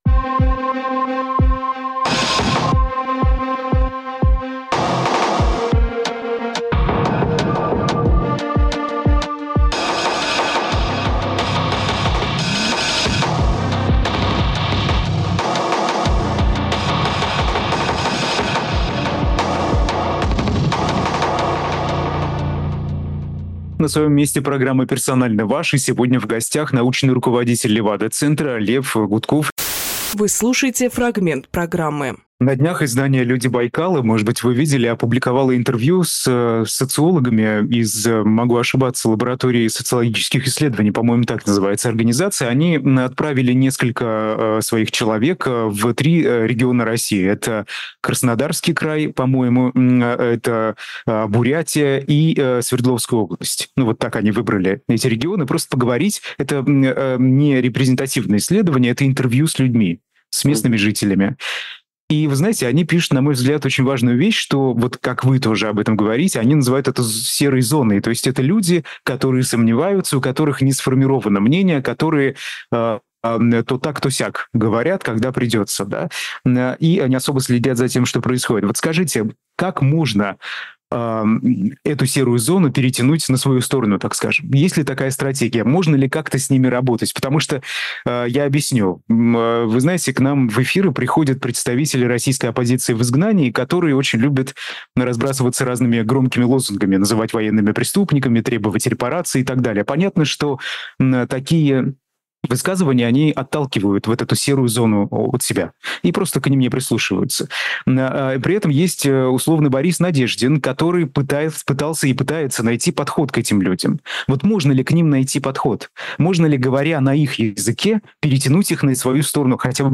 Фрагмент эфира от 05.06.24